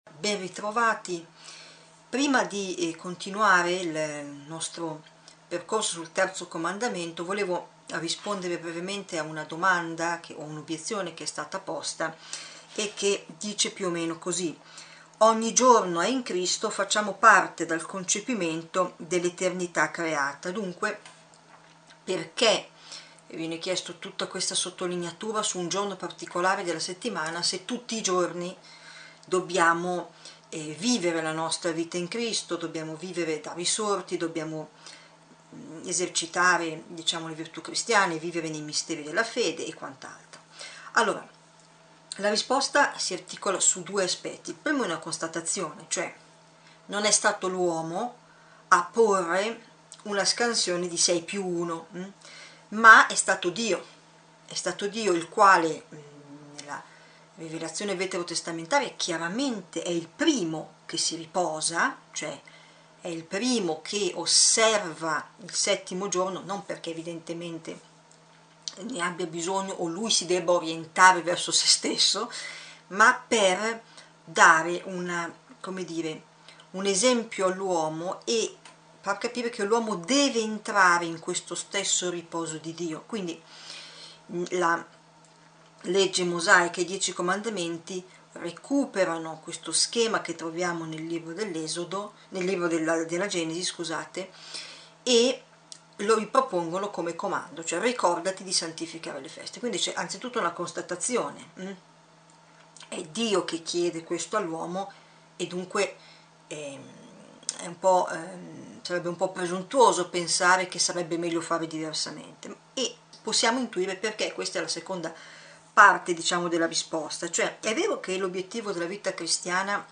Catechesi adulti
EUCARISTIA-DOMENICALE-E-RIPOSO-Lezione-46-del-Catechismo.mp3